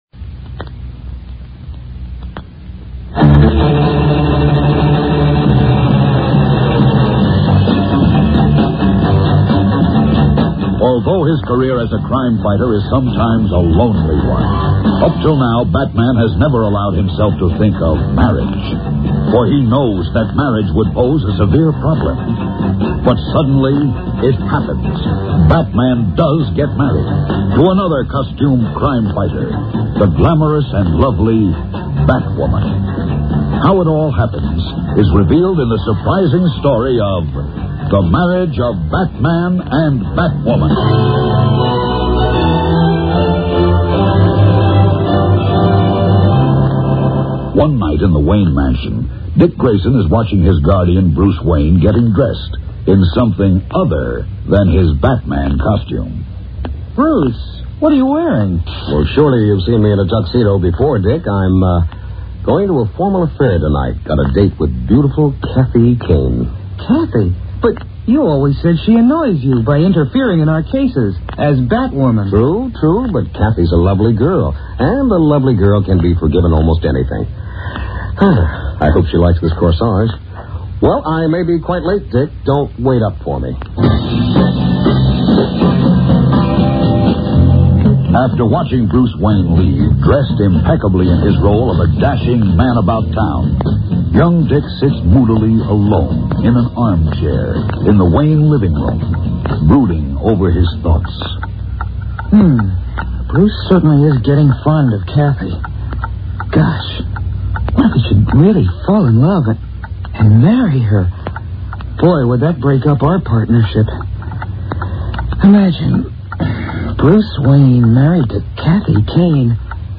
Dark Knight of the Airwaves: The Batman Audio Dramas
Diane Pershing as Batwoman in this alternate reality tale